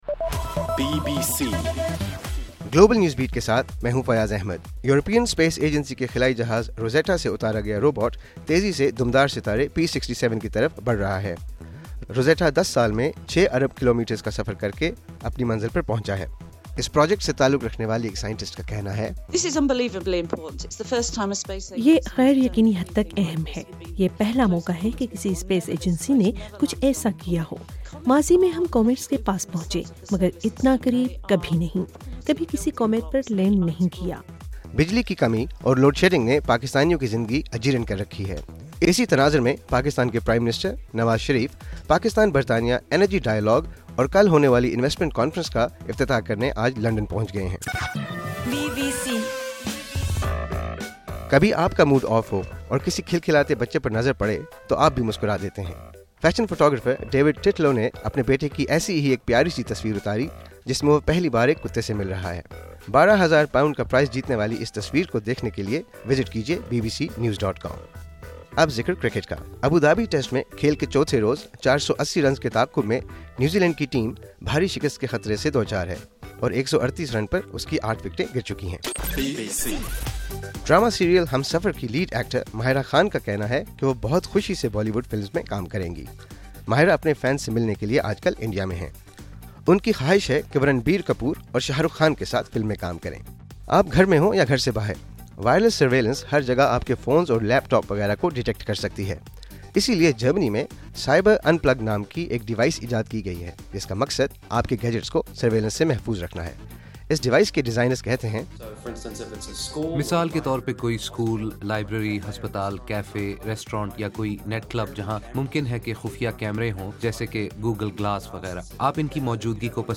نومبر12: رات 8 بجے کا گلوبل نیوز بیٹ بُلیٹن